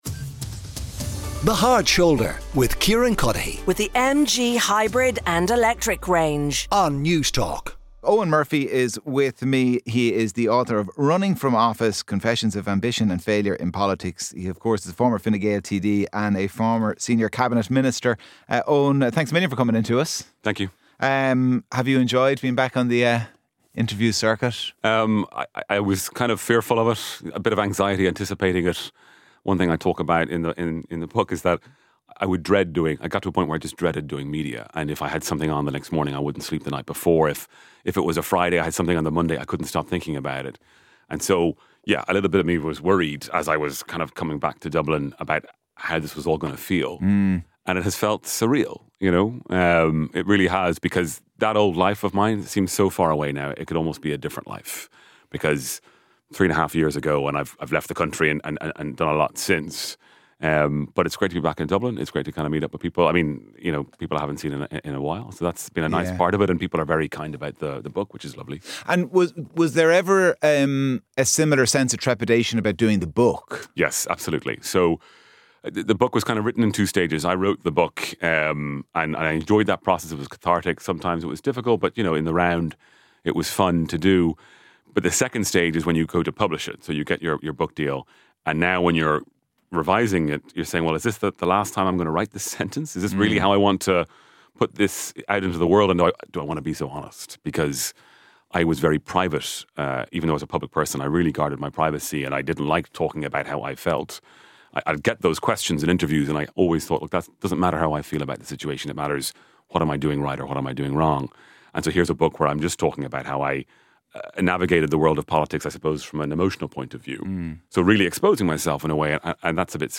The Thursday Interview on The Hard Shoulder - The Thursday Interview: Eoghan Murphy